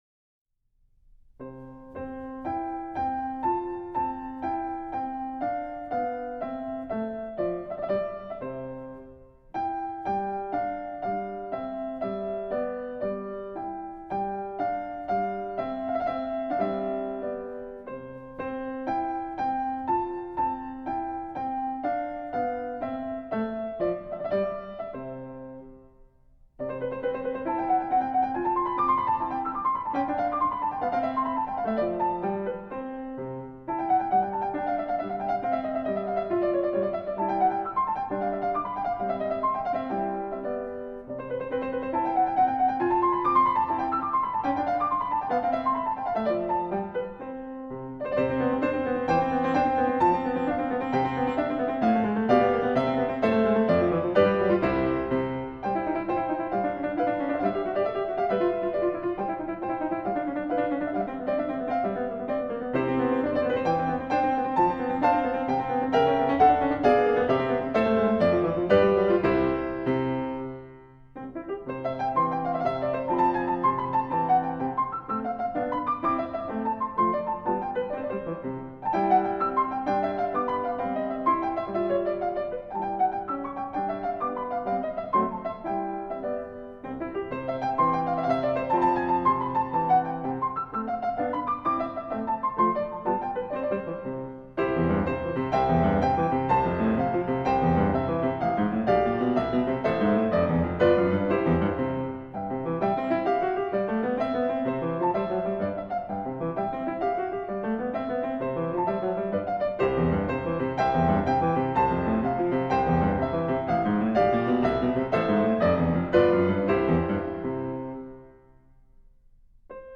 録音 2006年8月31日-9月2日 新潟小出郷文化会館